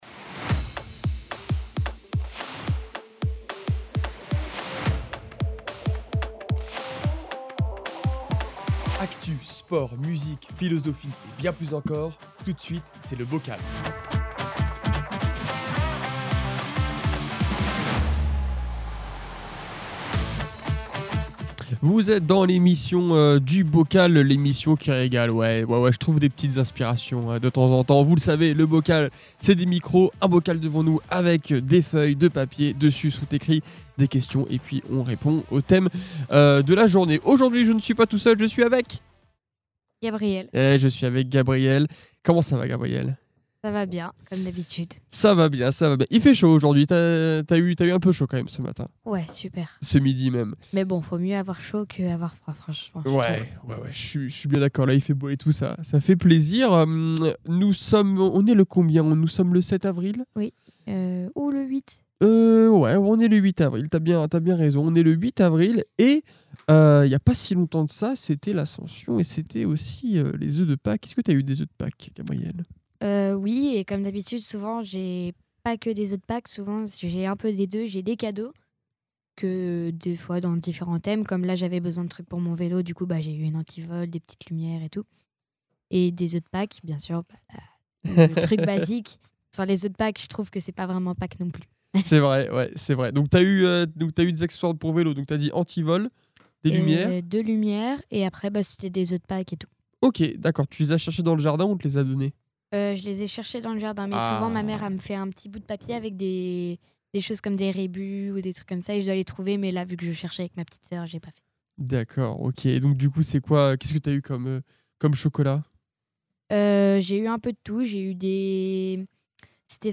Une poignée d'invités propose des sujets de débats à bulletin secret.
Le sujet, une fois dévoilé, donne lieu à des conversations parfois profondes, parfois légères, toujours dans la bonne humeur !